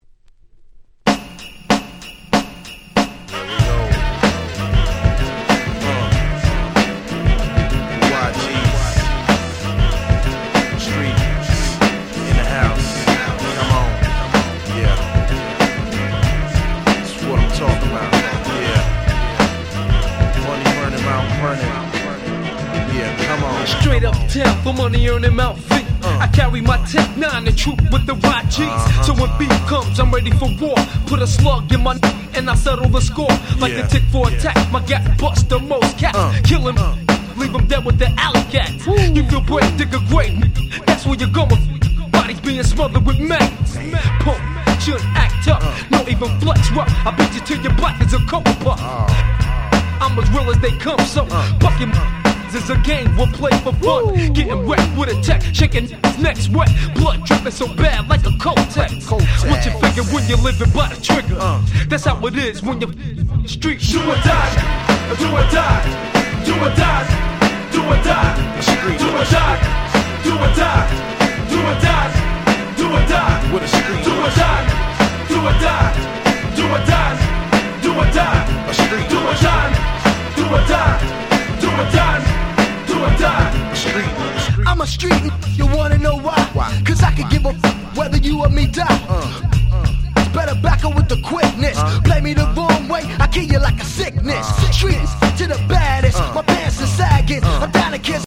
93' Nice Hip Hop !!